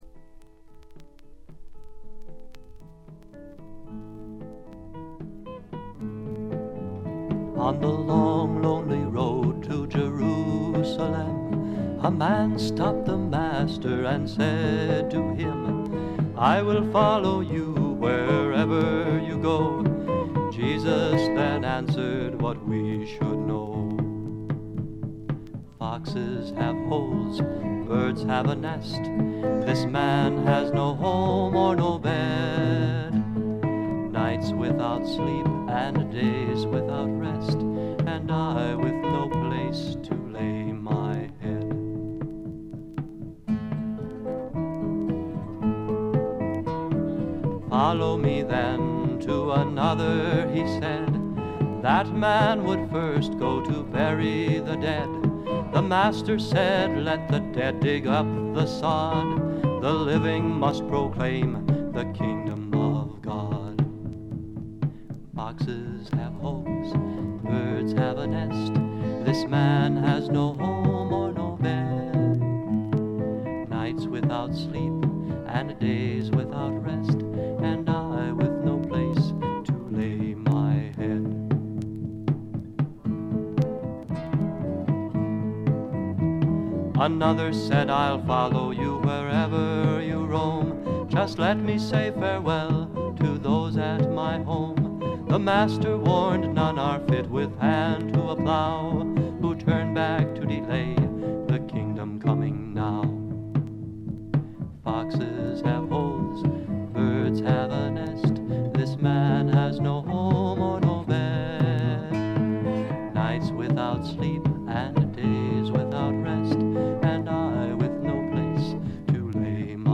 軽微なバックグラウンドノイズやチリプチ少々、プツ音2回ほど。
試聴曲は現品からの取り込み音源です。
Vocals, Twelve-String Guitar, Kazoo
Lead Guitar
Bass Guitar
Percussion
Recorded At - United Theological Seminary